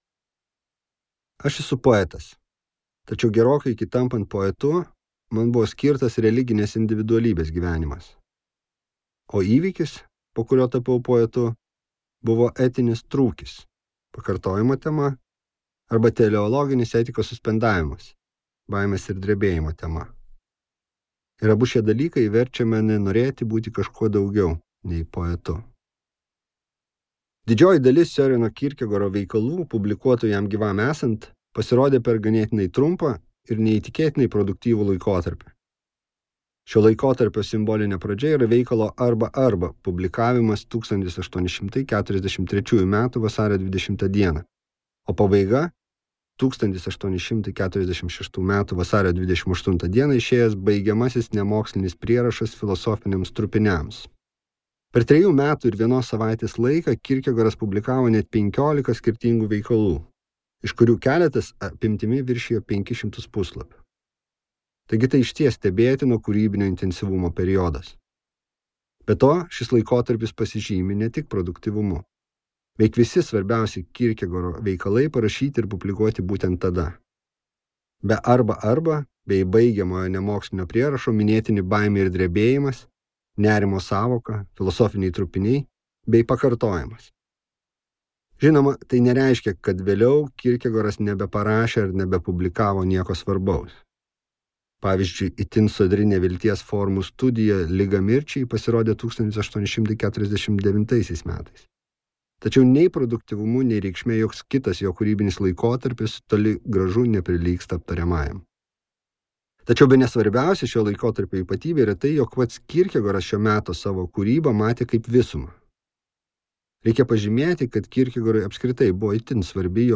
Pakartojimas | Audioknygos | baltos lankos
Audio Pakartojimas